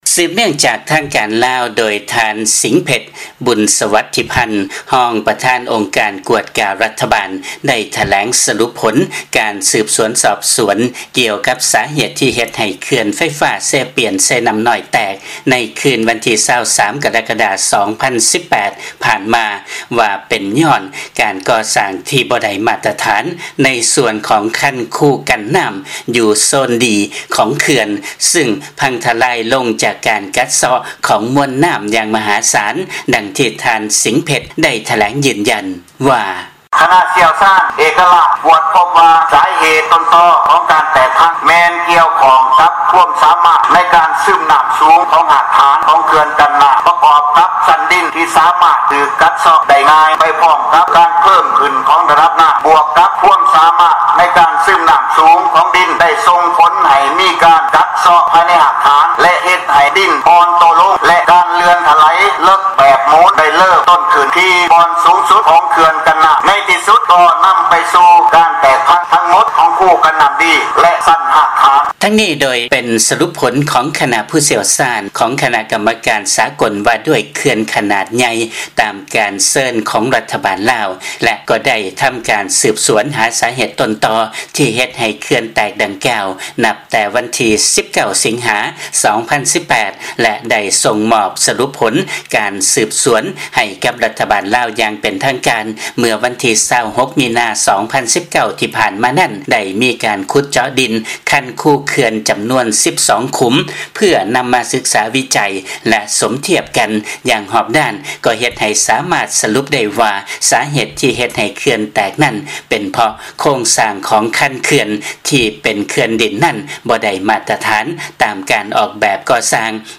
ຟັງລາຍງານ ບໍລິສັດລົງທຶນຈາກ ເກົາຫຼີໃຕ້ ບໍ່ເຫັນດ້ວຍ ກັບສະຫຼຸບ ສາເຫດເຂື່ອນ ເຊປຽນ-ເຊນ້ຳນ້ອຍ ແຕກ